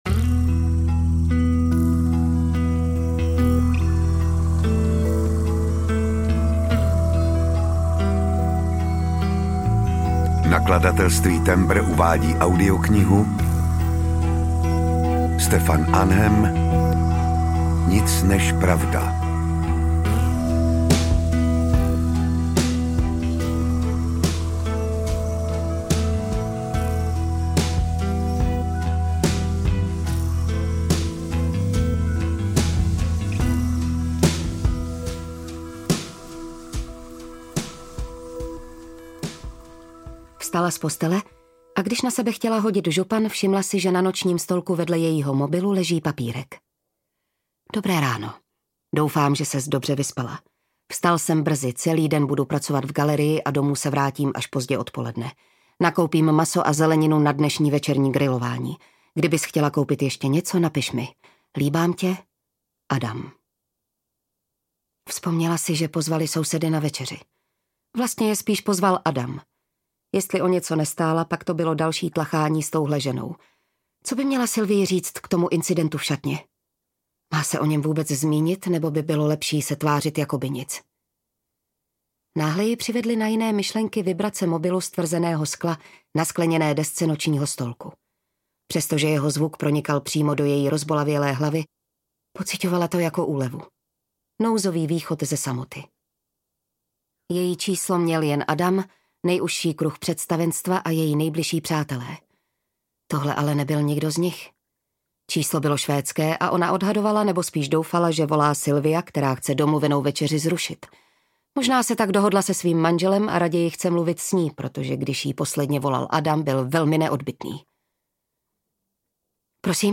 Nic než pravda audiokniha
Ukázka z knihy
nic-nez-pravda-audiokniha